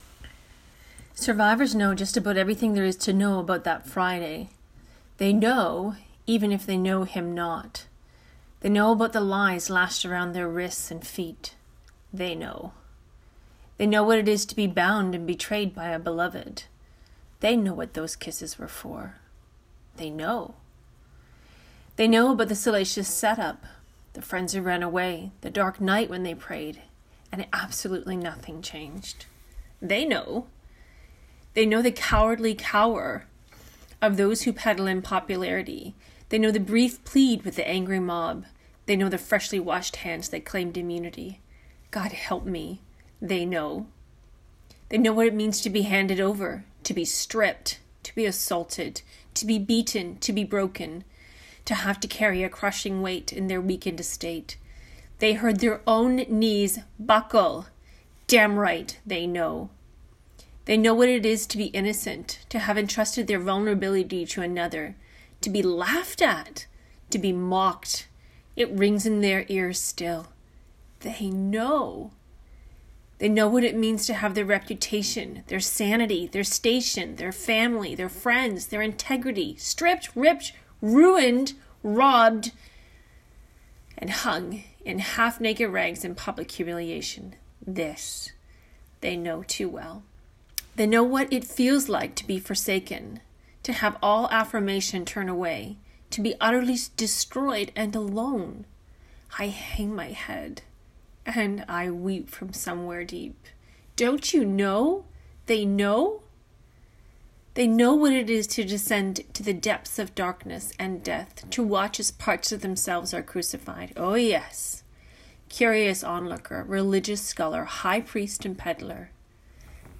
I have prepared this spoken word as a special gift for you.